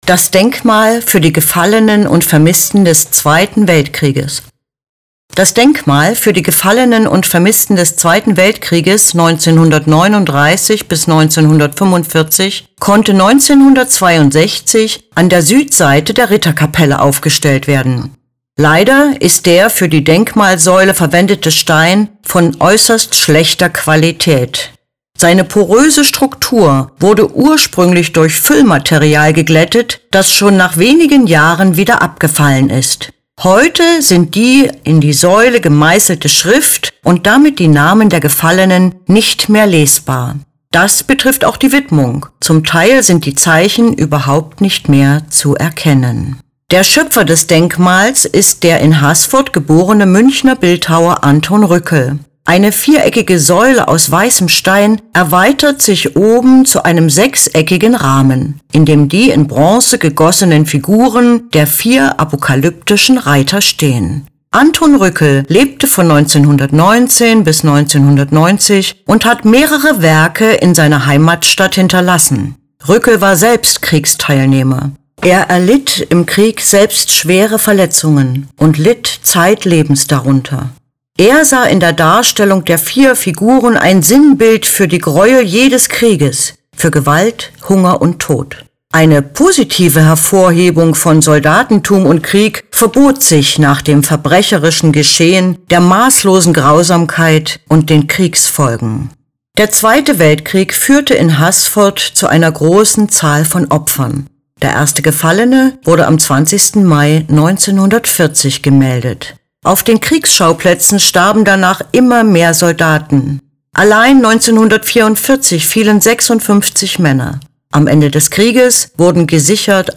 Lassen Sie sich die Infos zu diesem Werk einfach vorlesen.